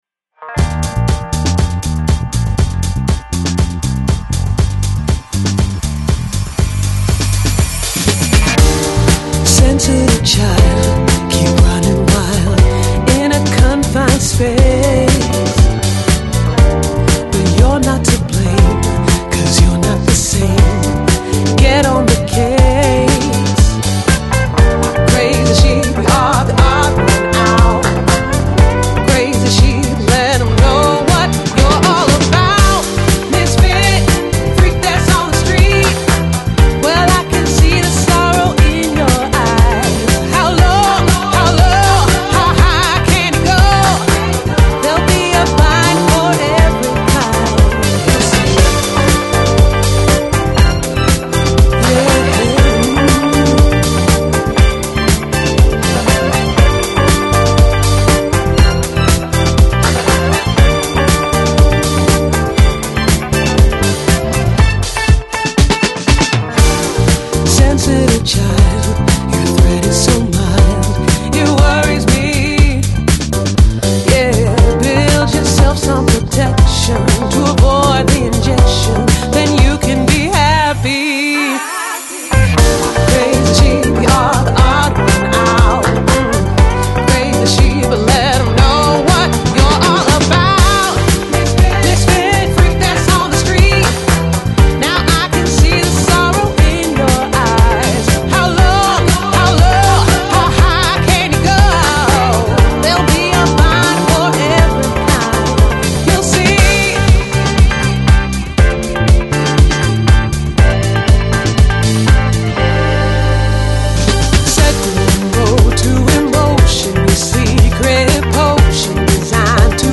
A Selection of Today's Hits in a New Lounge Version
Downtempo, Lounge, Nu Jazz, Easy Listening